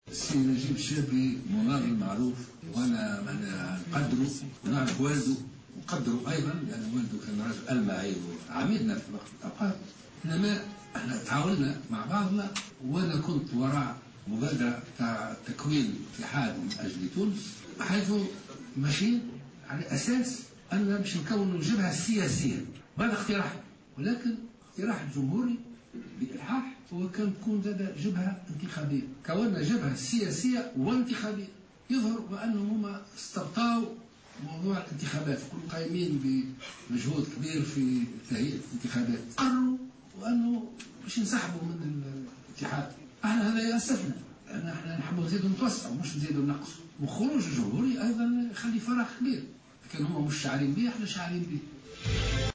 Lors d'une conférence de presse, le président de Nidaa Tounes Béji Caied Essebsi s'est arrêté sur les raisons qui auraient plus ou moins motivé le retrait du parti Al Jomhouri du front de salut national.